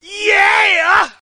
Tags: ASA Sports announcing